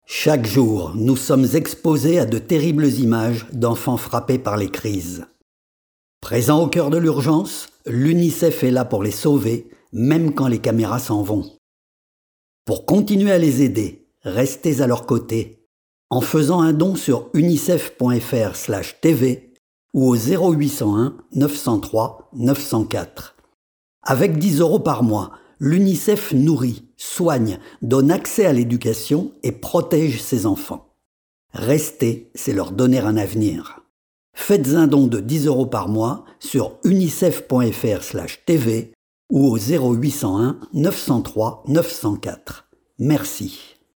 Voix off
- Baryton